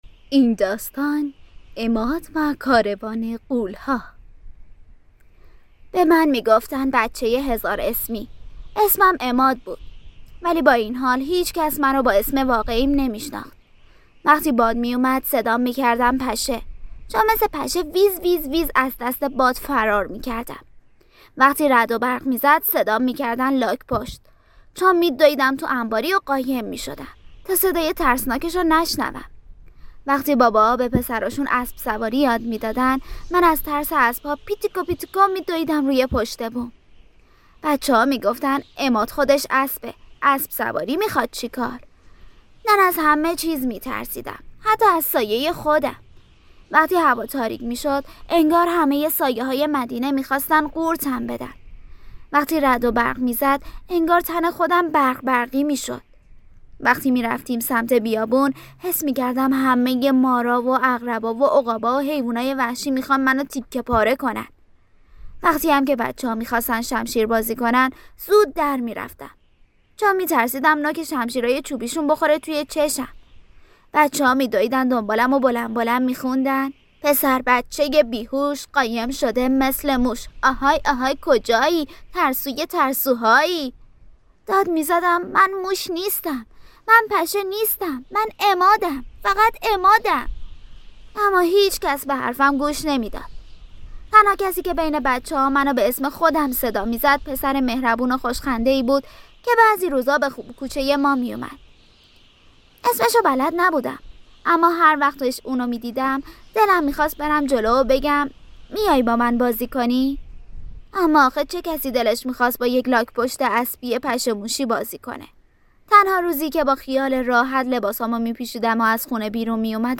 داستان صوتی عماد و کاروان غول ها